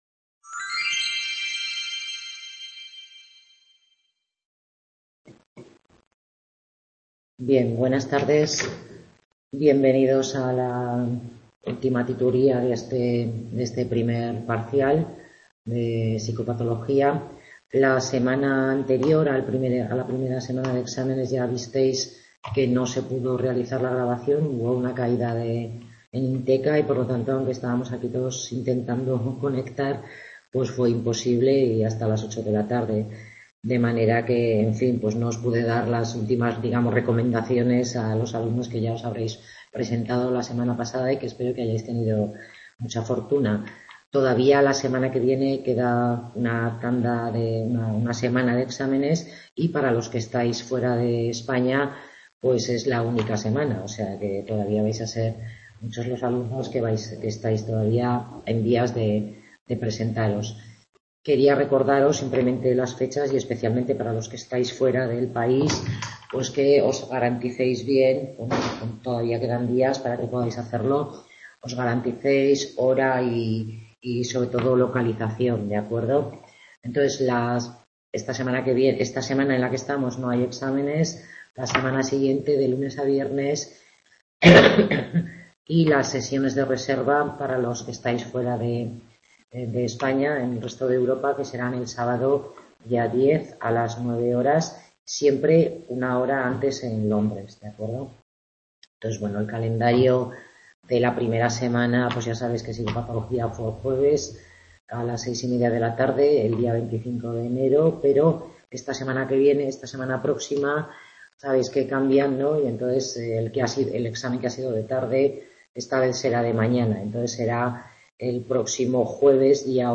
Tutoría grupal de Psicopatología 31-01-18 Orientaciones para el examen de la primera prueba personal